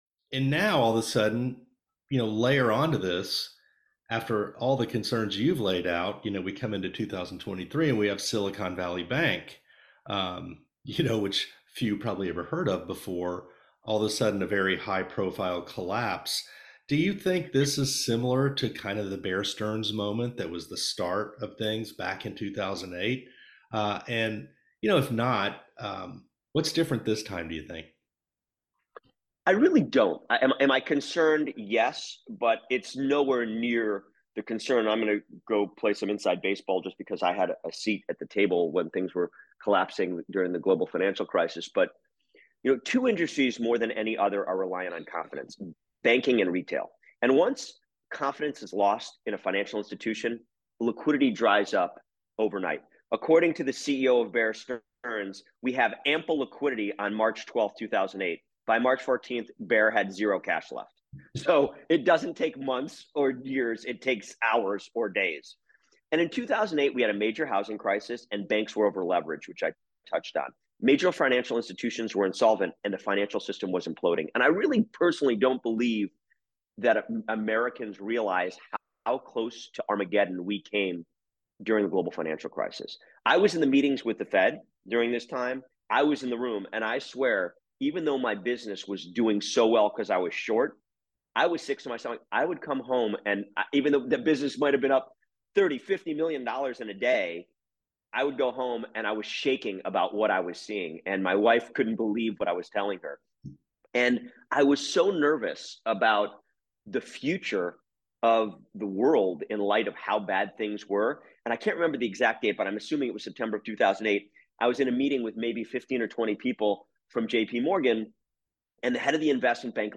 A couple of weeks ago, I was interviewed on a myriad of topics and one was to compare today’s banking issues with the Global Financial Crisis (GFC). This is the 8-minute clip of my answer which outlines a lot of issues. The short answer is NO, we are not close to the GFC.